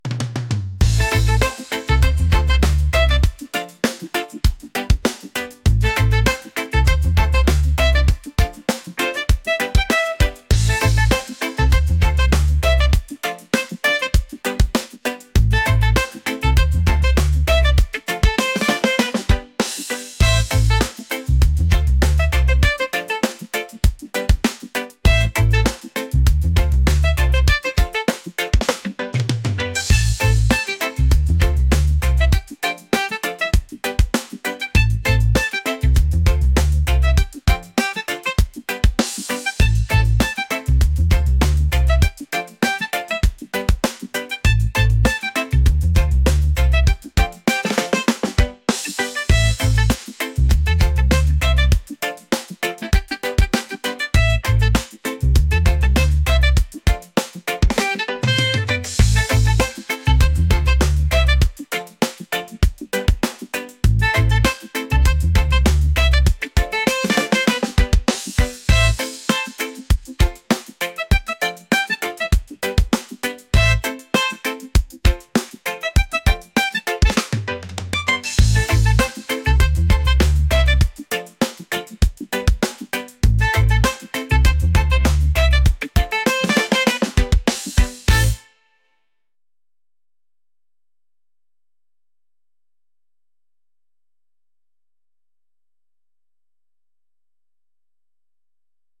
reggae | groovy | upbeat